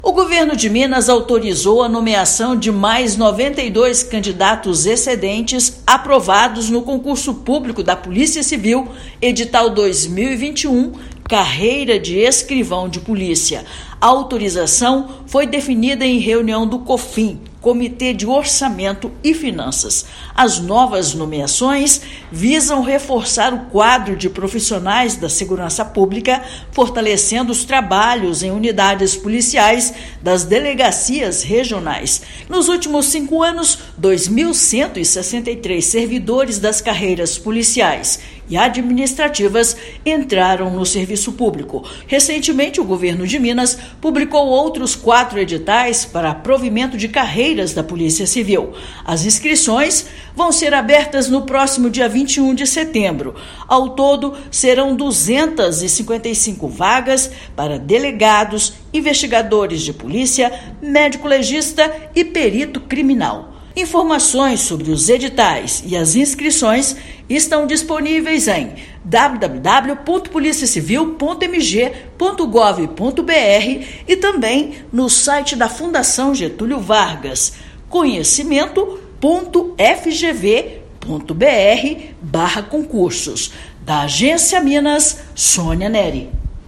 Candidatos aprovados para o cargo de escrivão podem ser chamados para fortalecer a atuação da polícia judiciária em todo o estado. Ouça matéria de rádio.